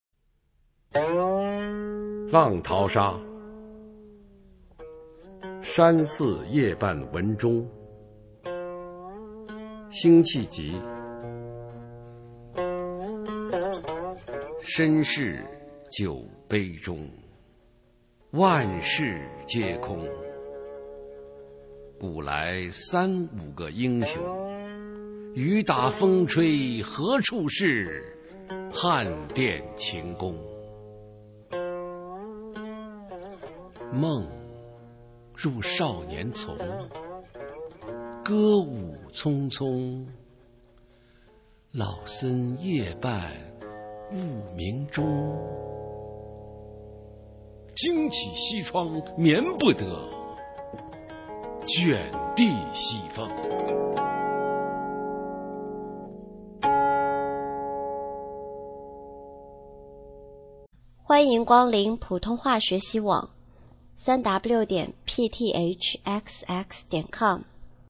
首页 视听 学说普通话 美声欣赏